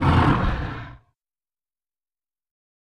PixelPerfectionCE/assets/minecraft/sounds/mob/guardian/elder_hit1.ogg at mc116
elder_hit1.ogg